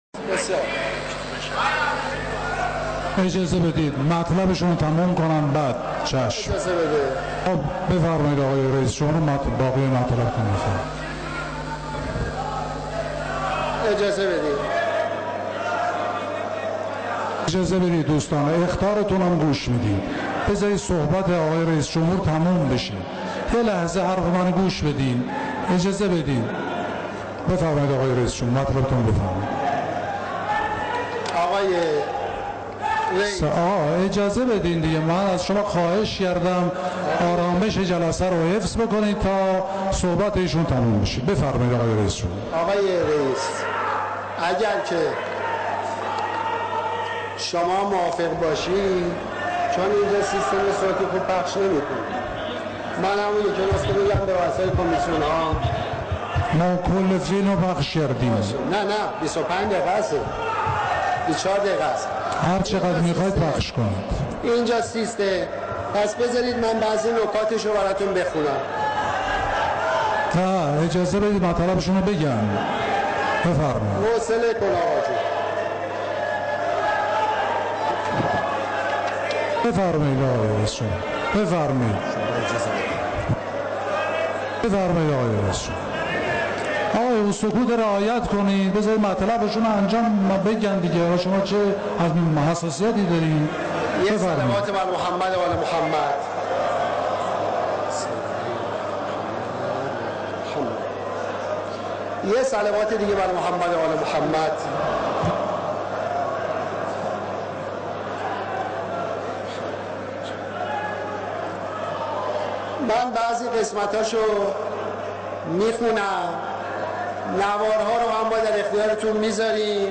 فایل کامل بخش جنجالی سخنان احمدی‌نژاد در جلسه استیضاح و پاسخ لاریجانی